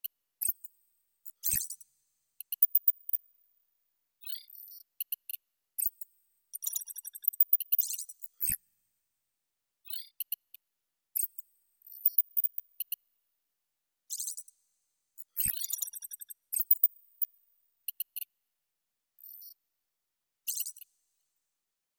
Анализирующий сканер турелей будущего nЗвуковые эффекты электронных турелей nЭлектронный сканер звука турелей nЗвук анализирующих турелей будущего